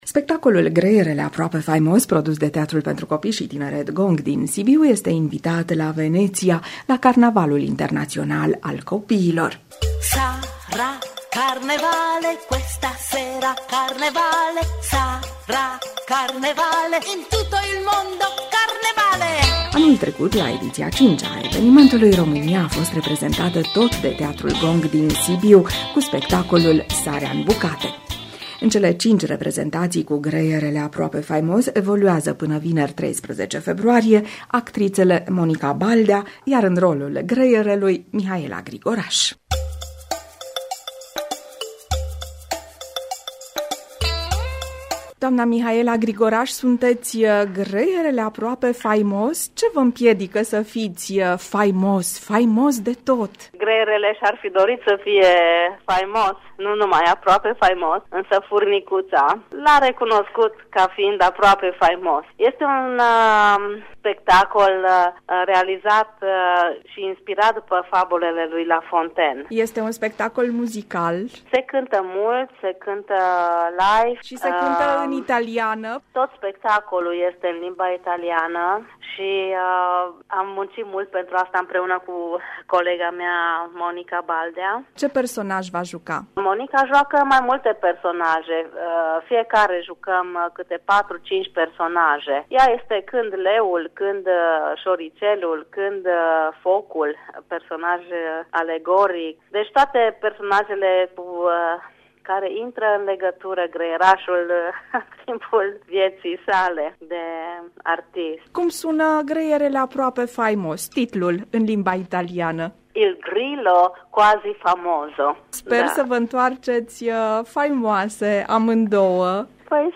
un reportaj pe această temă